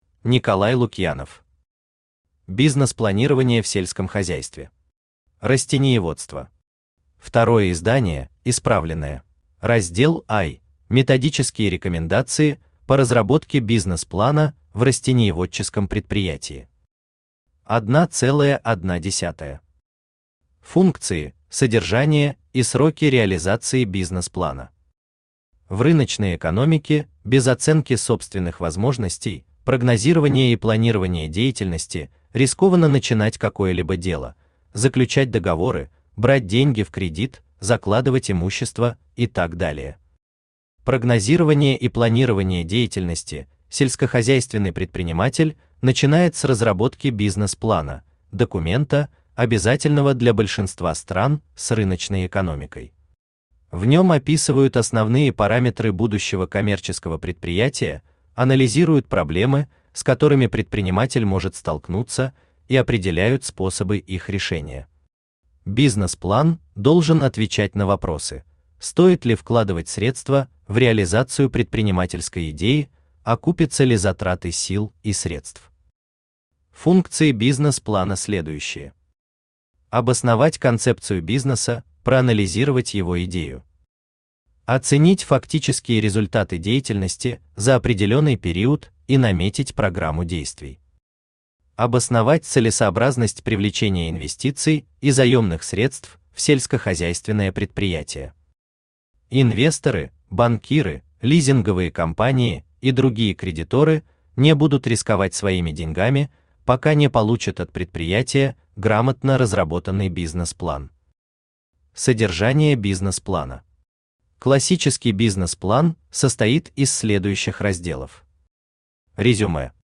Aудиокнига Бизнес-планирование в сельском хозяйстве. Растениеводство. Второе издание (исправленное) Автор Николай Вячеславович Лукьянов Читает аудиокнигу Авточтец ЛитРес. Прослушать и бесплатно скачать фрагмент аудиокниги